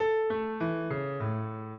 piano
minuet7-11.wav